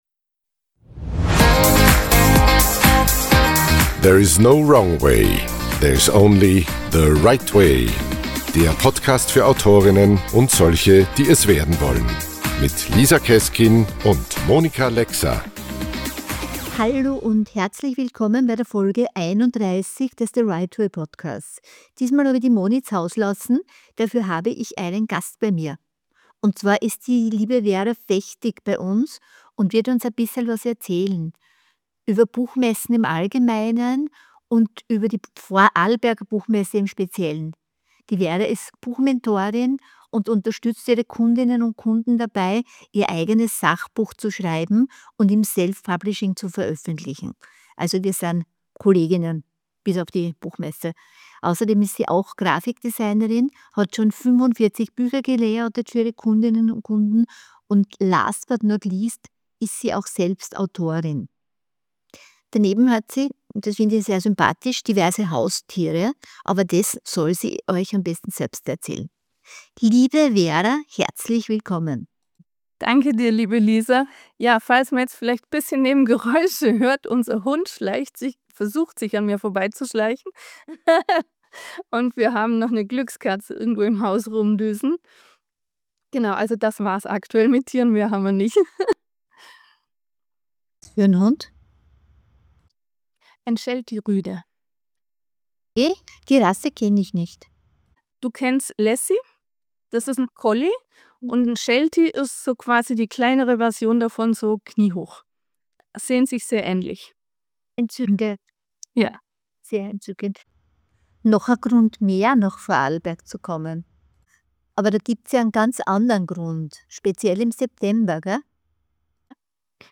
Im Gespräch mit ihr finden wir heraus, was Autorinnen, Verlagen und anderen Buchschaffenden eine Buchmesse bringen kann.